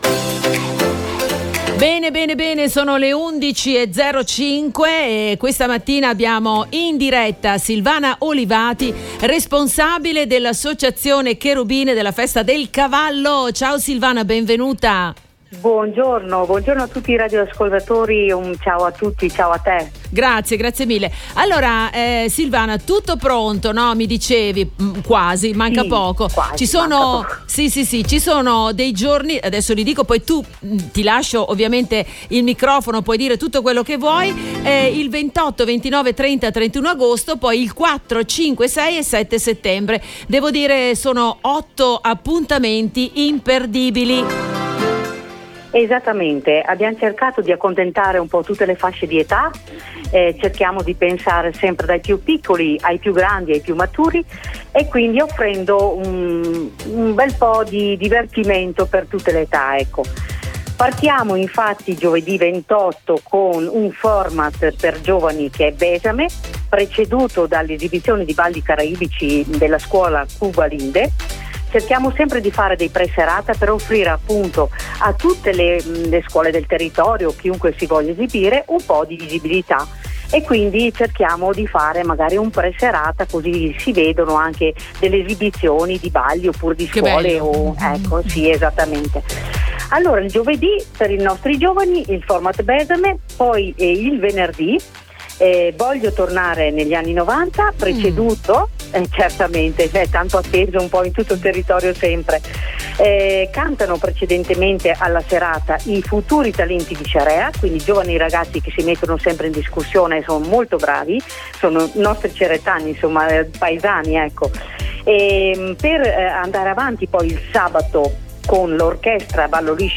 INTERVENTO IN DIRETTA